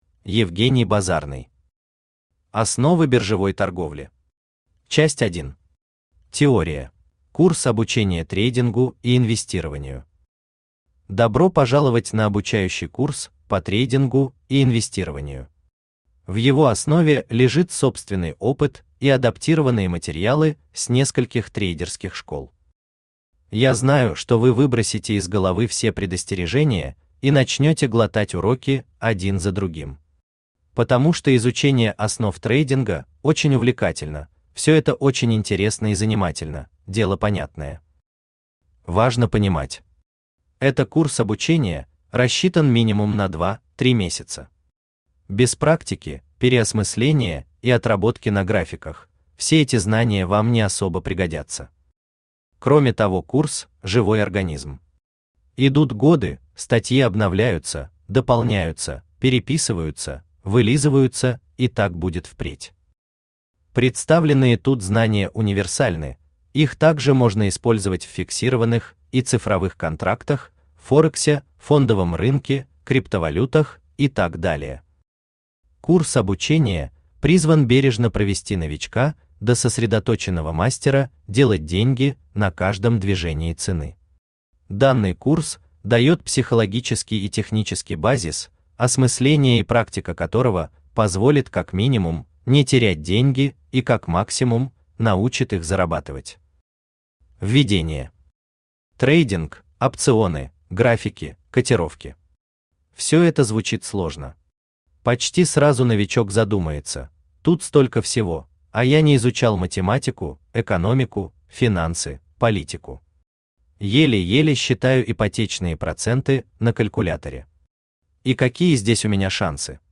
Теория Автор Евгений Базарный Читает аудиокнигу Авточтец ЛитРес.